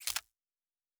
Weapon 16 Foley 2 (Laser).wav